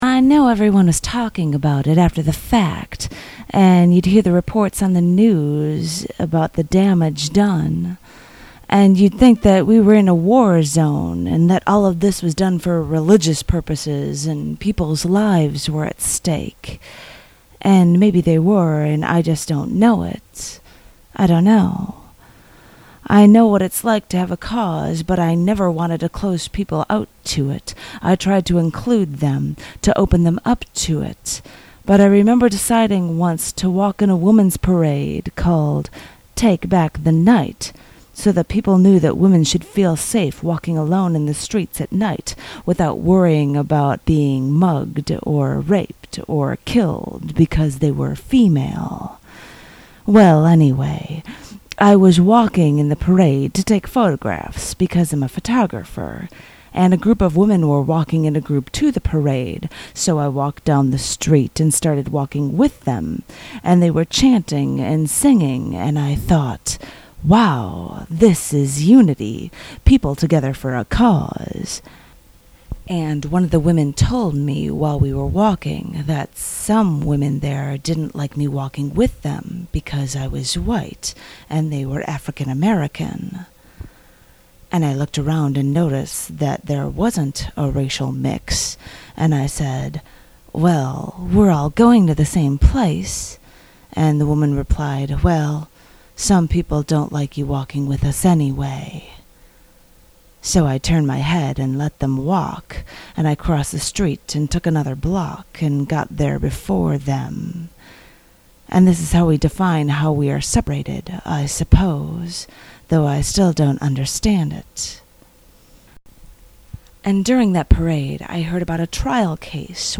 vocals track: